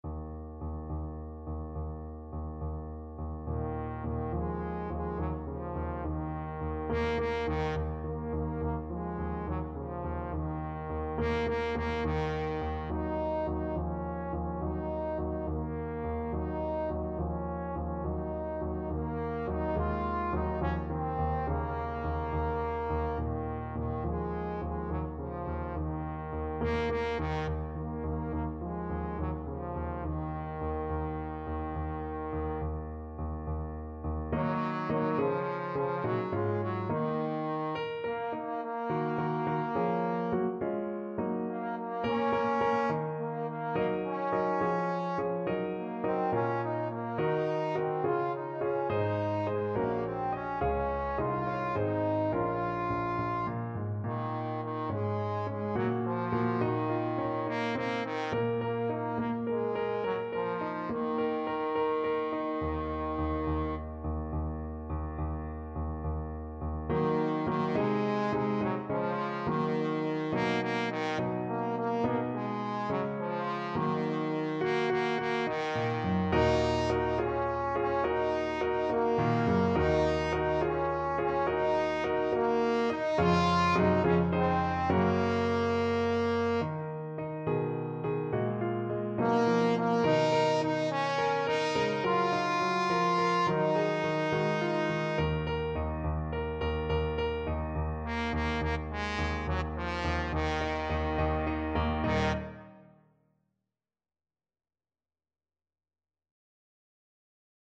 Trombone
Traditional Music of unknown author.
Quick one in a bar (. = c. 70)
Bb minor (Sounding Pitch) (View more Bb minor Music for Trombone )
3/4 (View more 3/4 Music)